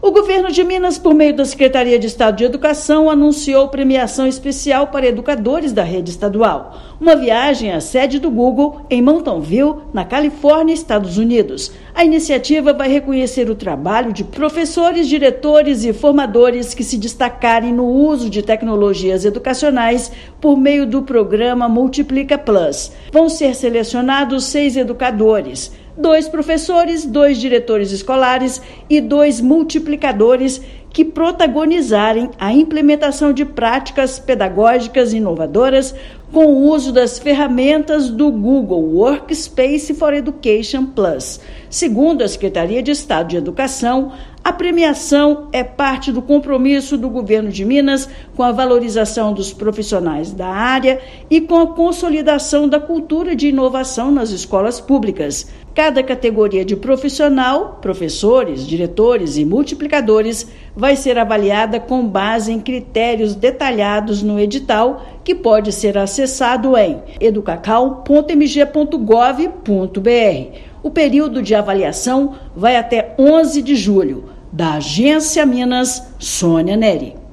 Reconhecimento valoriza educadores que promovem transformação digital no ensino público mineiro utilizando ferramentas do Google Workspace for Education Plus. Ouça matéria de rádio.